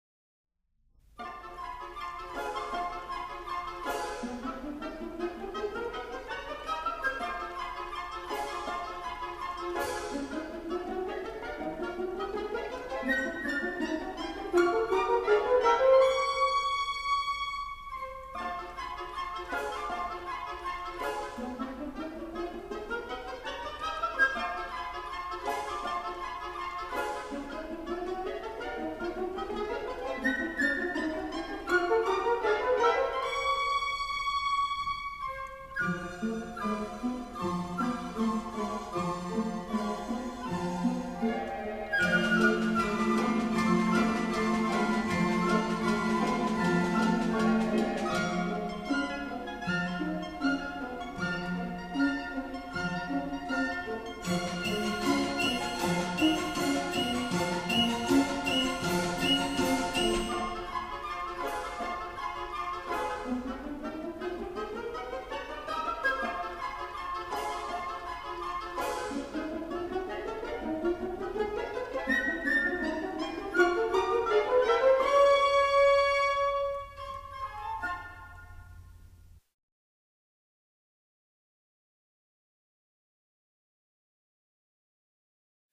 雏鸡在蛋壳里的芭蕾（Ballet de poussins dans leurs coques），谐谑而轻快活泼地，F大调。在这段音乐中，可以清楚地听出刚会走路的小鸡快活走动、鸣叫的高兴劲儿。